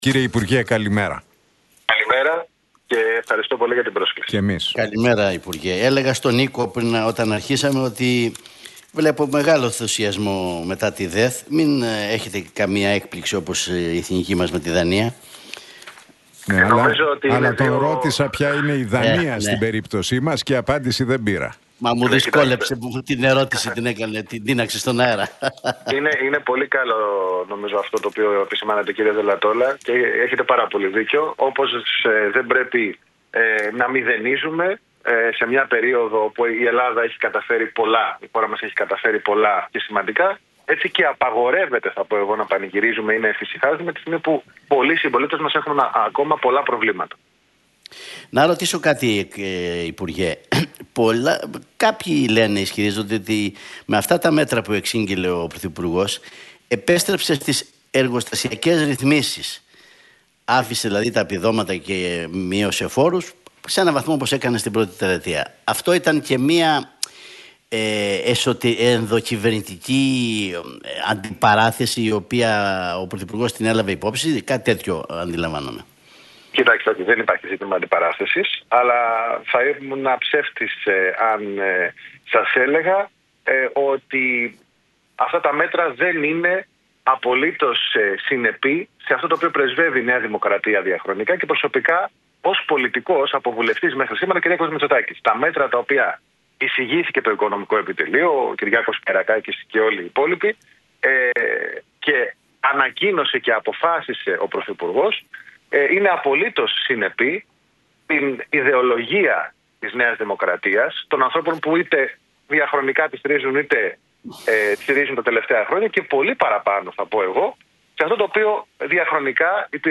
Για τις ανακοινώσεις του Πρωθυπουργού στην Διεθνή Έκθεση Θεσσαλονίκης και τα νέα μέτρα μίλησε ο Υφυπουργός παρά τω Πρωθυπουργώ και Κυβερνητικός Εκπρόσωπος,